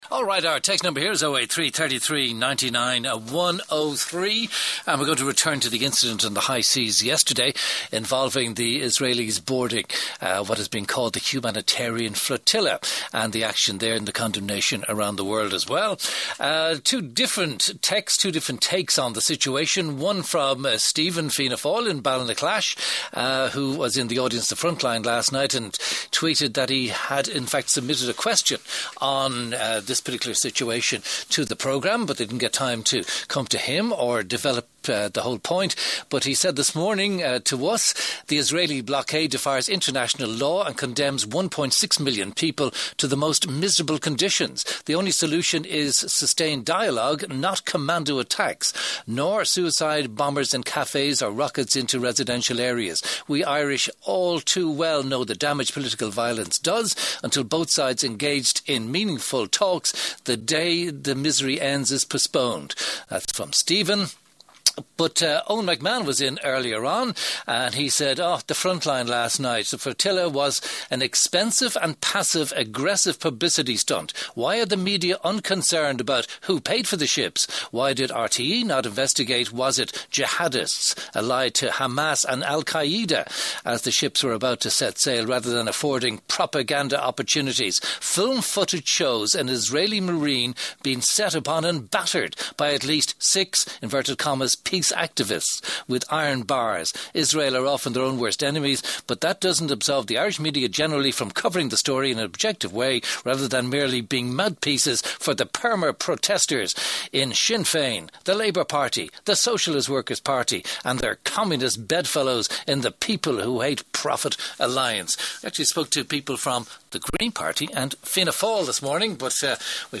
Debate on Gaza Situation
Categorized | Radio Interviews Debate on Gaza Situation Posted on 02/06/2010.